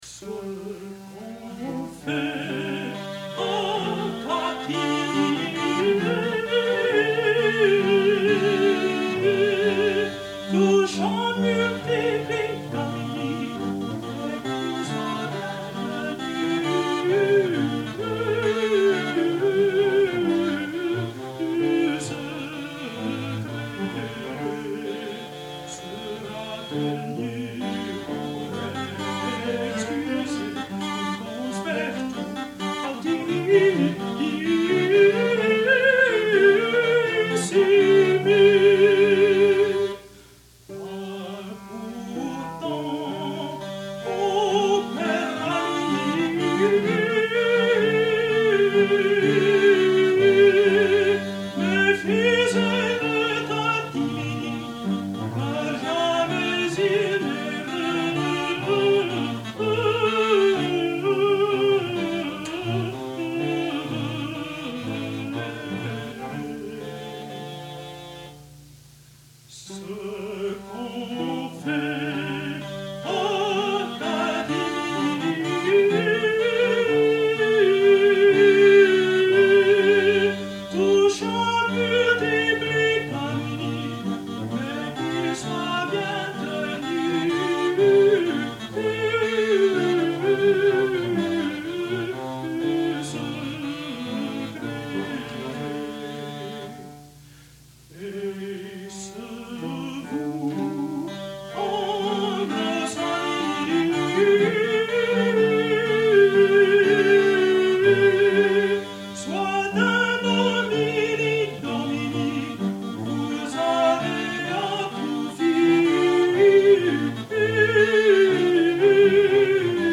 Chamber Consort
countertenor